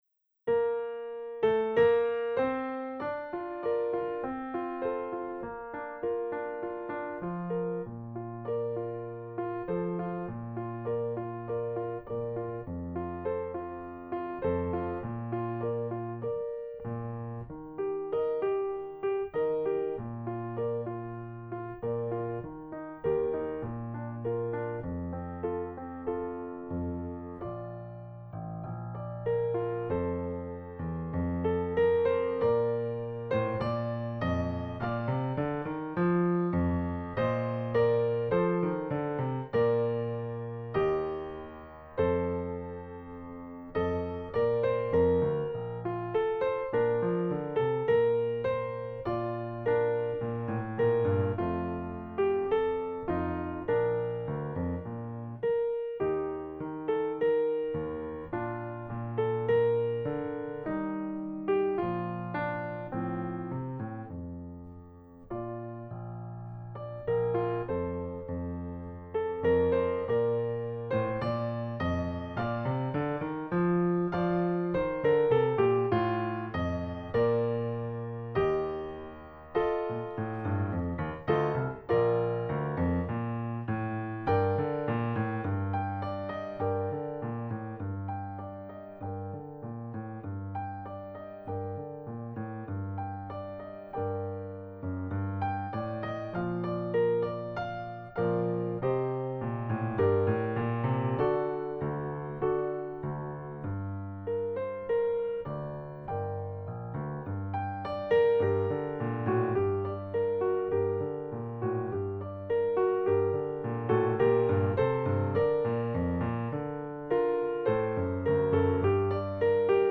They use a live pianist recording.
Andante con moto [3:40]
Audio: Piano part alone